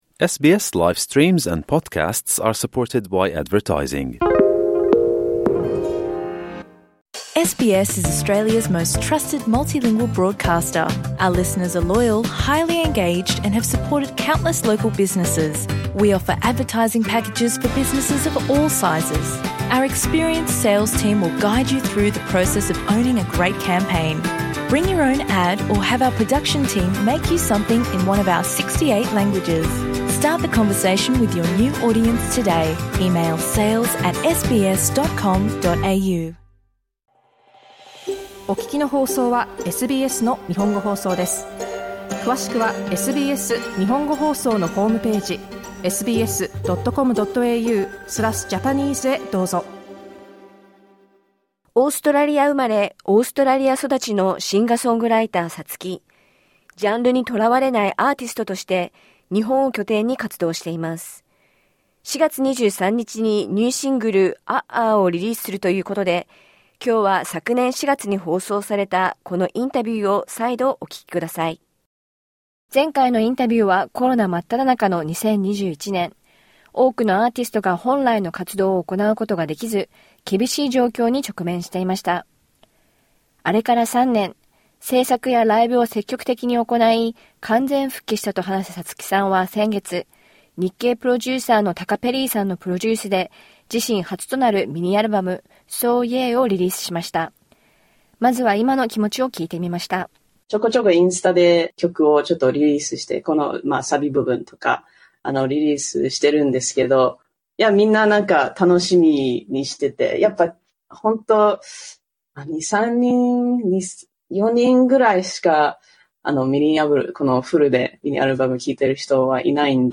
今回は、昨年4月に放送されたインタビューをあらためてお届けします。